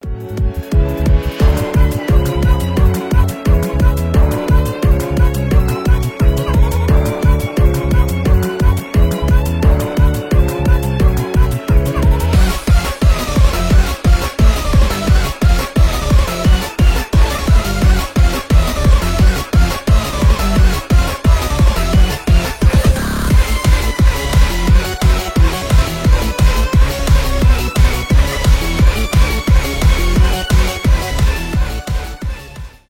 без слов
энергичные , из игр